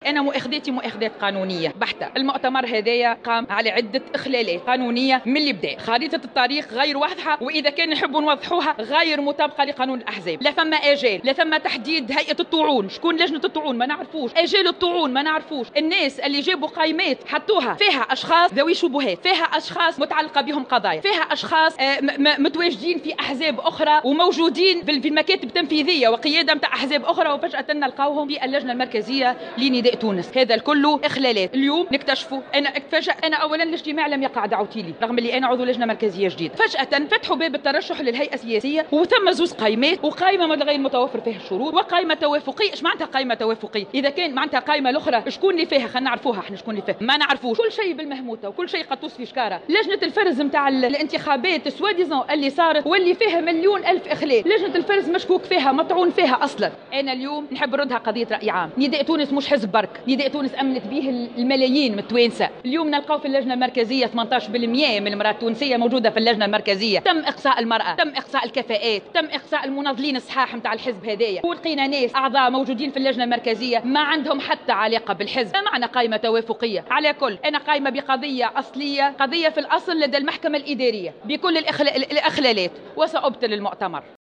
وأشارت في تصريح لمراسلة "الجوهرة اف أم" إلى اخلالات قانونية تتمثل في منشور غير واضح وخارطة طريق غير واضحة كما أنه لم يتم تشكيل لجنة طعون أو تحديد آجال الطعون في مخالفة لقانون الأحزاب، وفق تعبيرها. واستنكرت خروج مؤتمر الحزب الانتخابي بقائمة توافقية تضمّ شخصيّات من أحزاب أخرى أو تعلّقت بهم تتبعات قضائية.